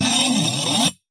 描述：金属效果使用台虎钳固定锯片和一些工具来击打，弯曲，操纵。 所有文件都是96khz 24bit，立体声。
Tag: 研磨 尖叫 金属 耐擦 效果 声音